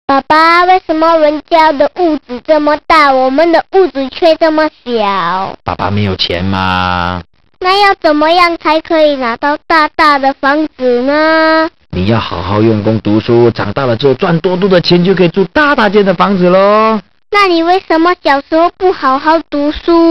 搞笑铃声